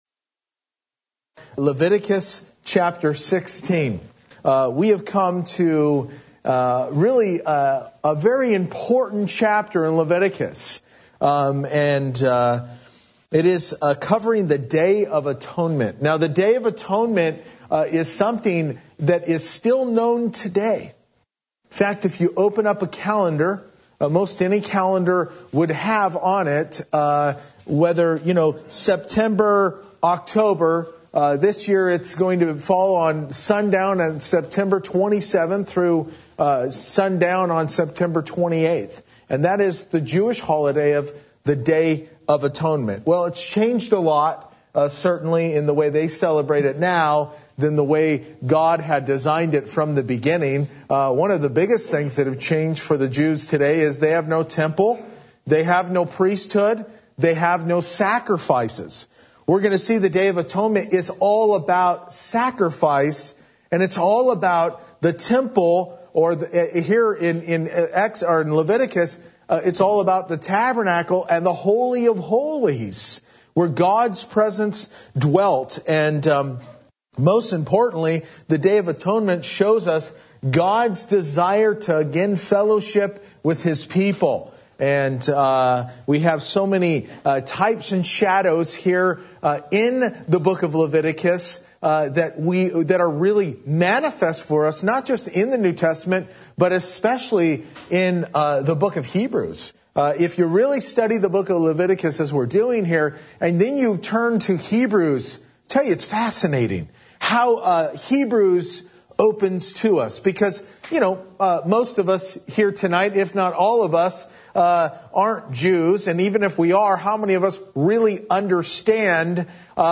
Verse by Verse-In Depth « Leviticus 12-15 Mosaic Health Laws Leviticus 17-18 & 20 Separated Unto The Lord